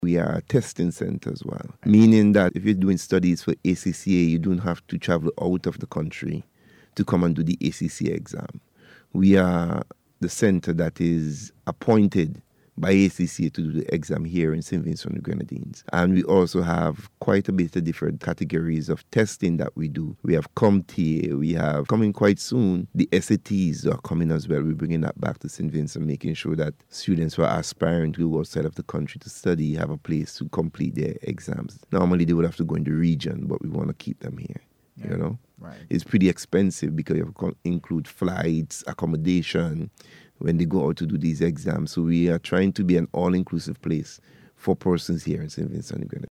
Speaking on NBC’s Talk Yuh Talk programme